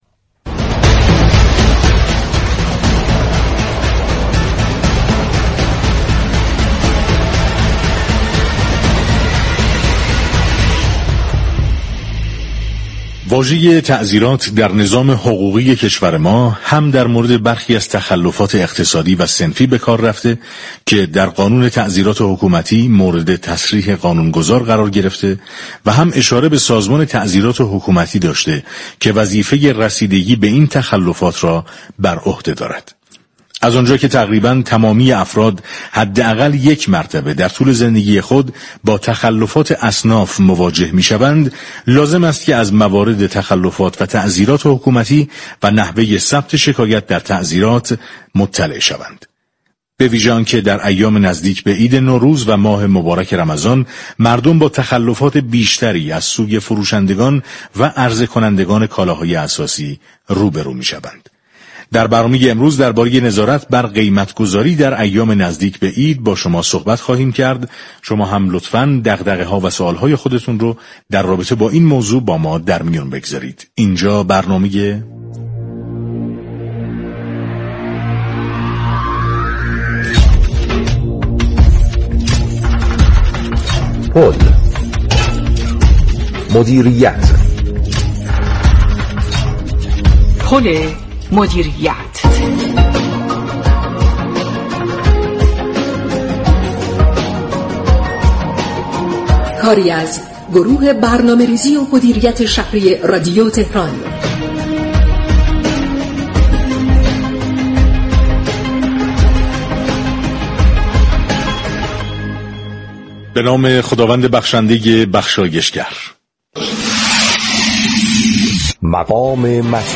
به گزارش پایگاه اطلاع رسانی رادیو تهران، احمد جعفری نسب معاون نظارت، بازرسی و برنامه‌ریزی سازمان تعزیرات كشور در گفت و گو با برنامه پل مدیریت 24 اسفندماه درباره حوزه اختیارات و وظایف سازمان تعزیرات حكومتی اظهار داشت: مجموعه تعزیرات، مجموعه‌ای دادرسی محور و رسیدگی به تخلفات اقتصادی، صنفی، بهداشتی و قاچاق است.